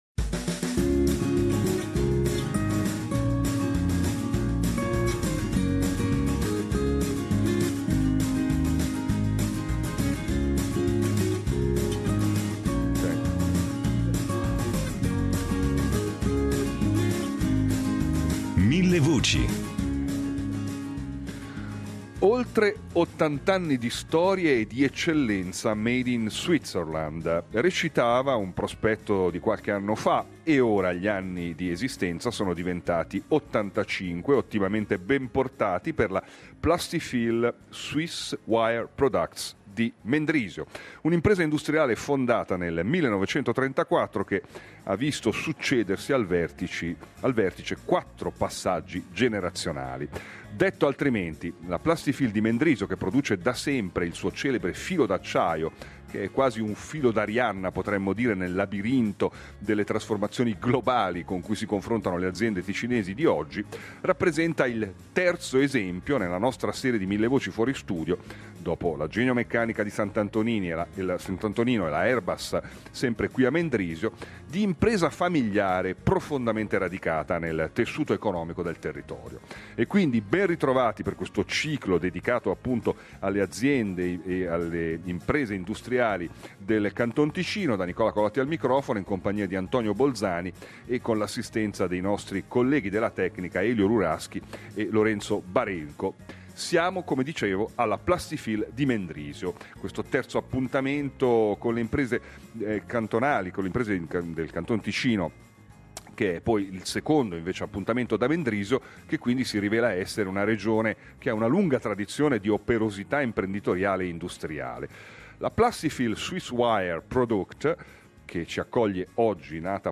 In diretta dalla Plastifil di Mendrisio